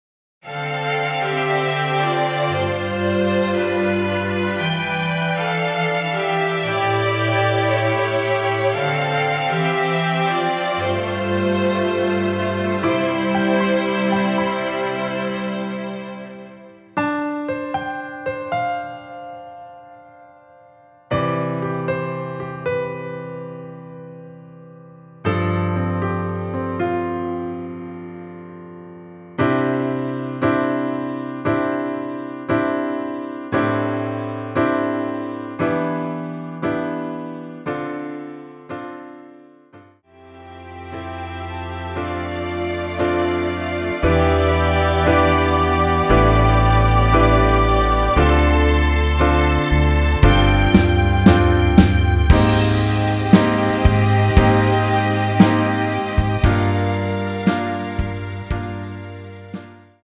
2절 부분은 삭제하고 어둠이 찾아 들어로 연결 됩니다.(가사및 미리듣기 참조)
앞부분30초, 뒷부분30초씩 편집해서 올려 드리고 있습니다.
중간에 음이 끈어지고 다시 나오는 이유는